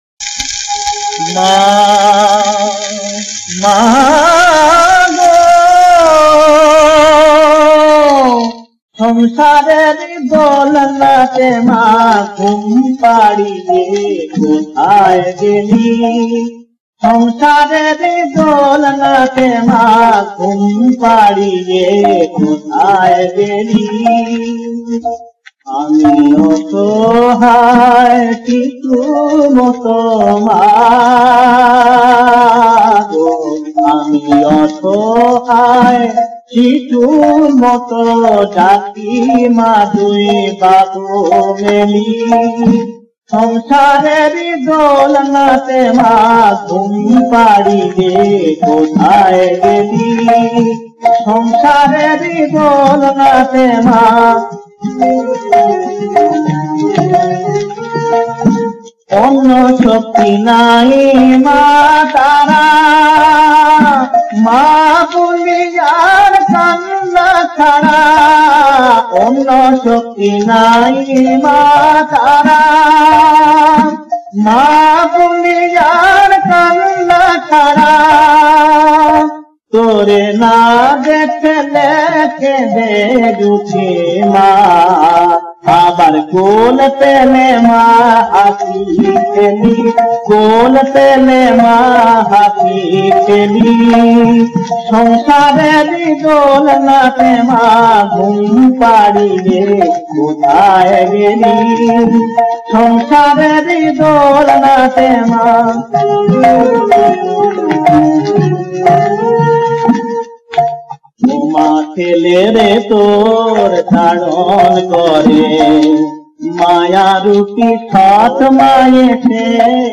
• বিষয়াঙ্গ: ভক্তি [হিন্দুধর্ম, শাক্ত]
• সুরাঙ্গ: ভজন